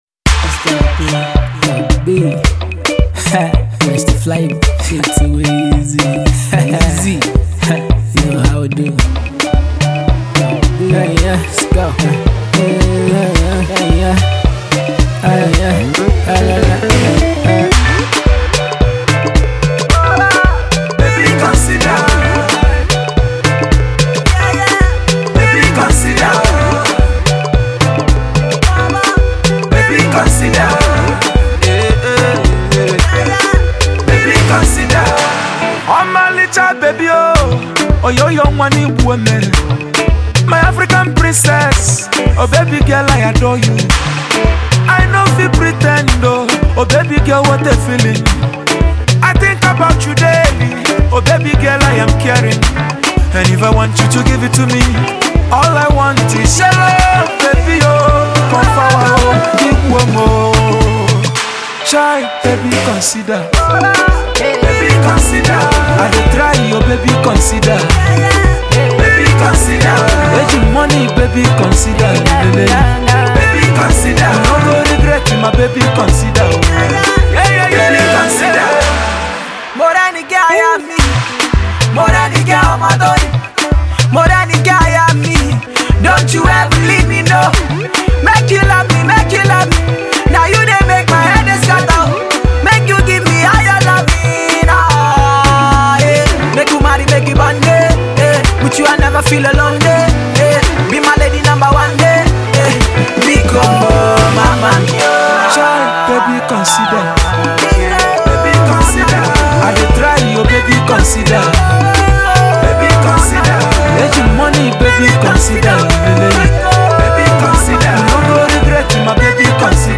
ballad